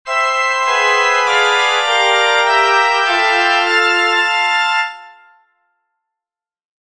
Antizipation (lateinisch: Anticipatio, "Vorausnahme") nennt man in der Harmonielehre die Vorausandeutung einer Harmonie, den verfrühten Eintritt von Tönen, die dem auf die nächste schwere Zeit folgenden Akkord angehören, und die zu der Harmonie, während deren sie eintreten, meist dissonieren, aber korrekterweise gar nicht auf sie bezogen, sondern als vorausgenommen, "antizipiert" verstanden werden, zum Beispiel:
Natürlich sind solche Antizipationen akzentuiert zu bringen.